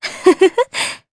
Aselica-Vox_Happy2_jp.wav